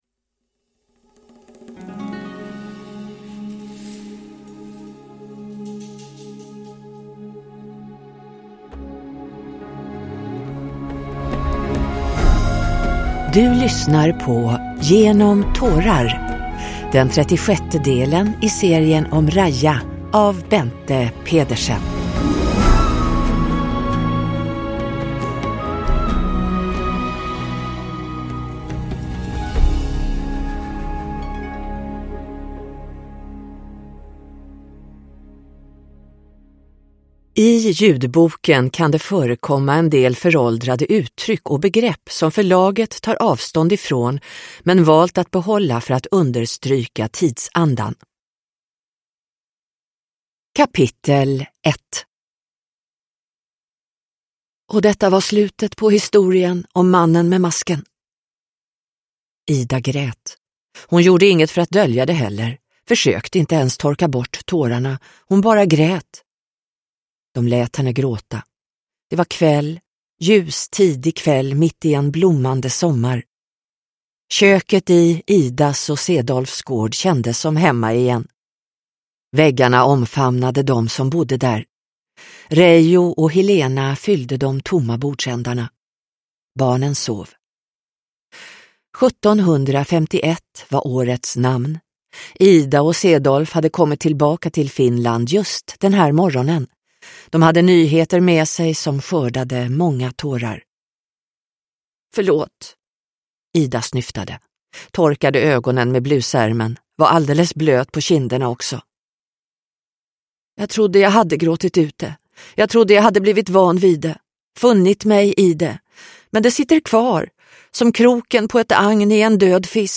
Genom tårar – Ljudbok – Laddas ner